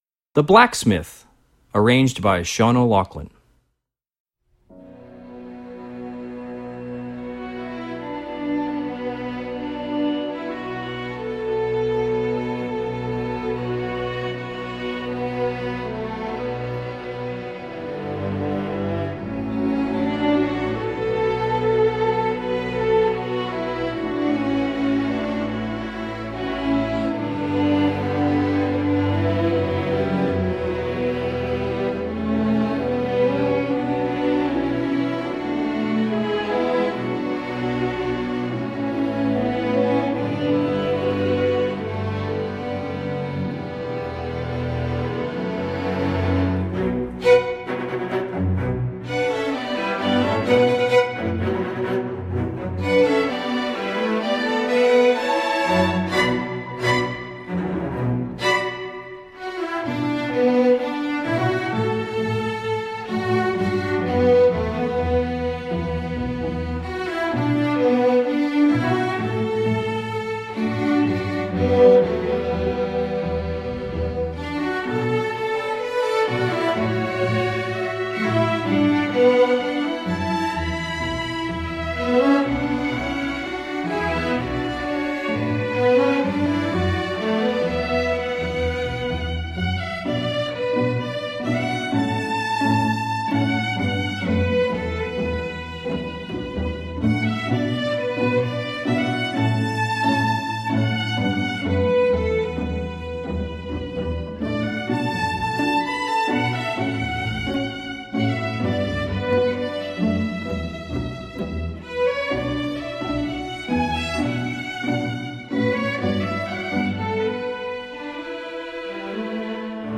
Composer: English Folk Song
Voicing: String Orchestra